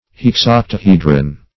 Search Result for " hexoctahedron" : The Collaborative International Dictionary of English v.0.48: Hexoctahedron \Hex*oc`ta*he"dron\, n. [Hex- + octahedron.]